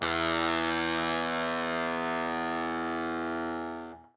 SPOOKY    AA.wav